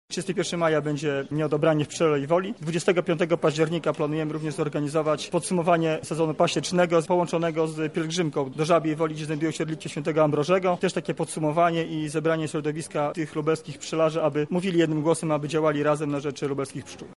Na to pytanie odpowiada Członek Zarządu Województwa Lubelskiego Sebastian Trojak.